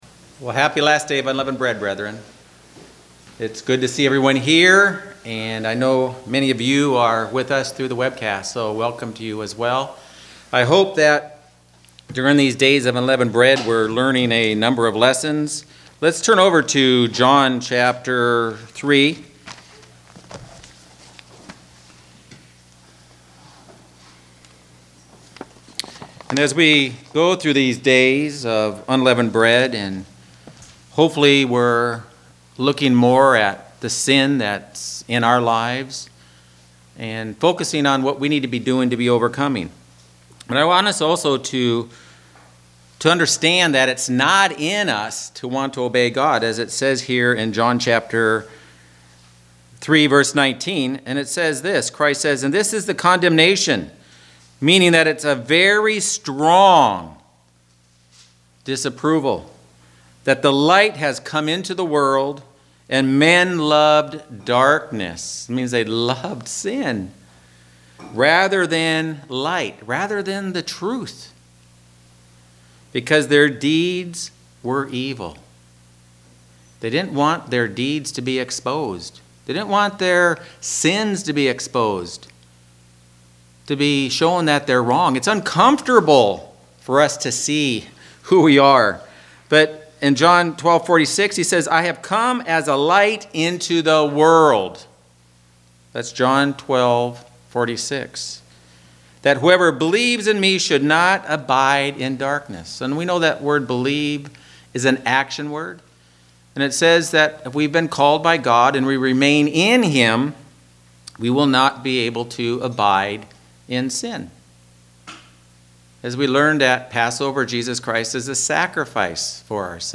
Sermons
Given in Northern Arizona Phoenix East, AZ